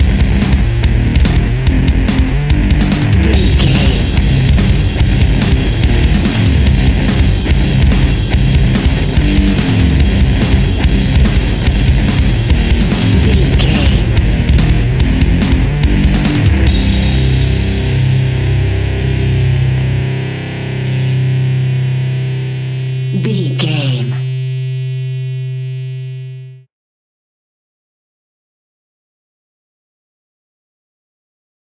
Fast paced
Aeolian/Minor
hard rock
heavy metal
distortion
rock instrumentals
rock guitars
Rock Bass
heavy drums
distorted guitars
hammond organ